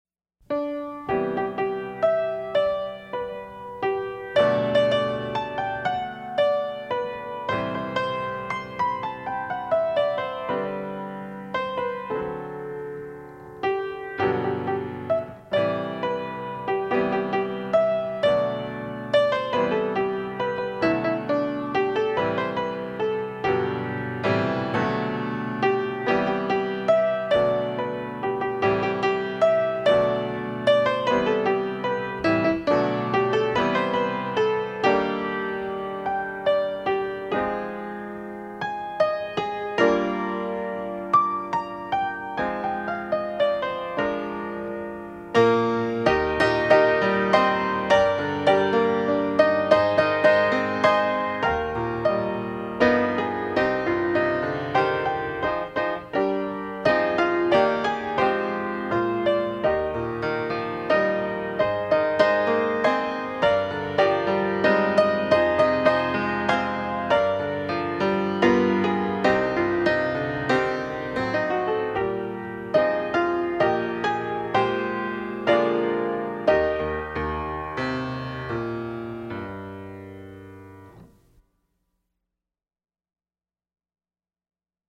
DIGITAL SHEET MUSIC - PIANO SOLO